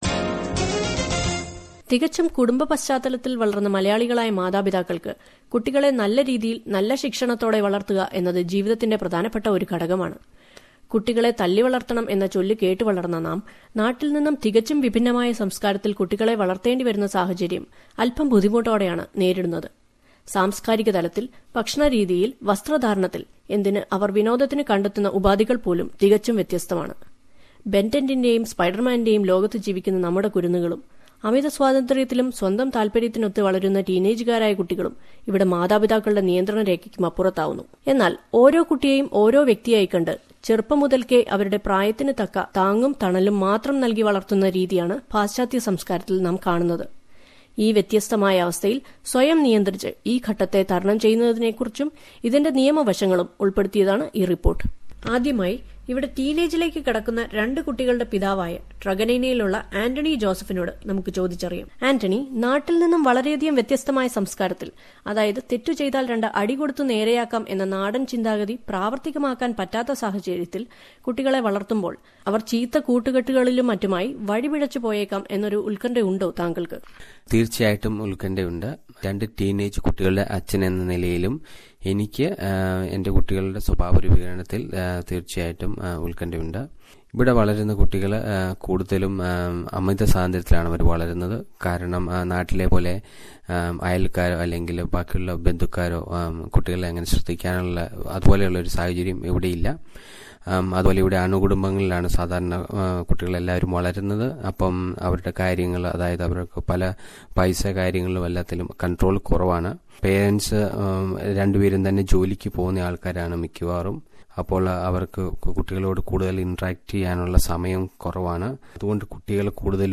Listen to a report that highlights the anxieties of parents and the legal side of punishing kids...